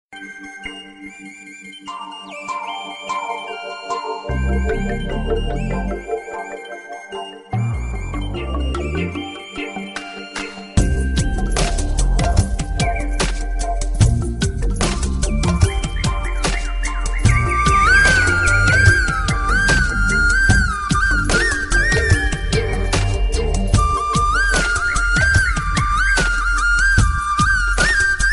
Musik, Android, Instrumentalmusik